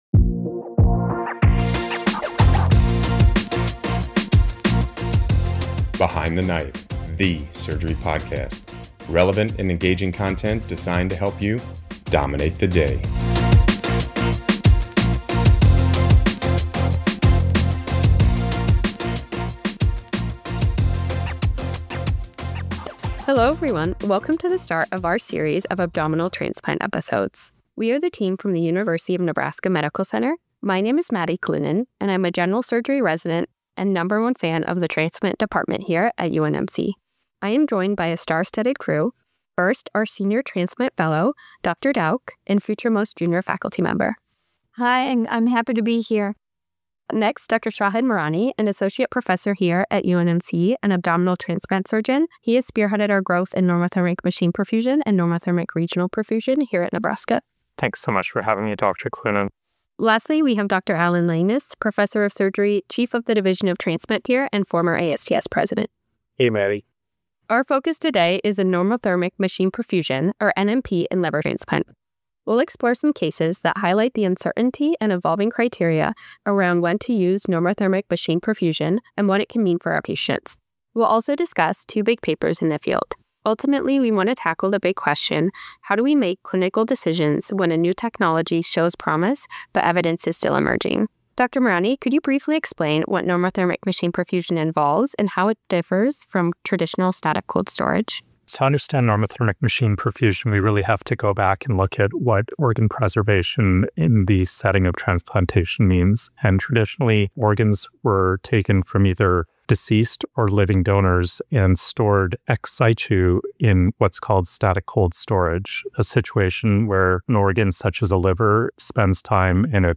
In this episode of Behind the Knife, our transplant team at University of Nebraska Medical Center discusses the latest evidence from randomized trials, practical applications in donation after circulatory death (DCD) grafts, and how NMP is shaping clinical decision-making in high-risk transplants.